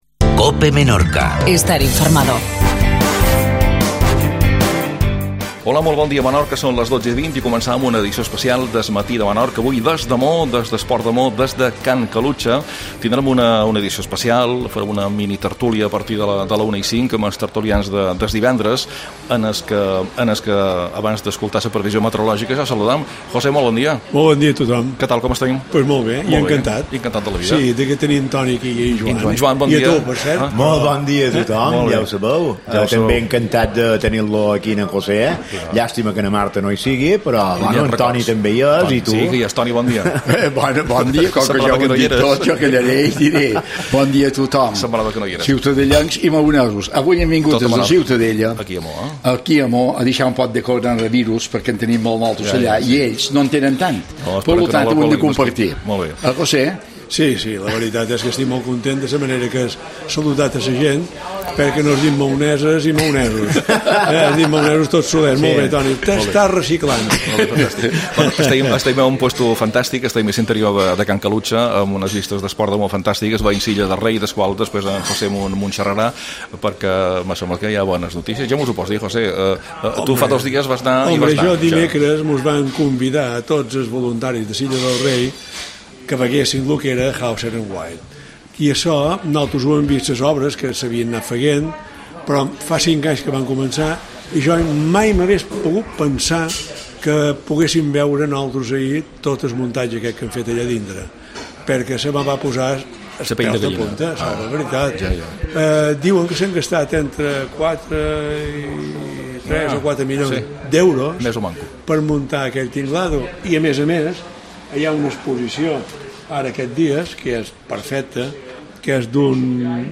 AUDIO: Programa especial des de Can Calucha, port de Maó. Primera part